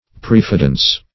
Search Result for " prefidence" : The Collaborative International Dictionary of English v.0.48: Prefidence \Pref"i*dence\, n. The quality or state of being prefident.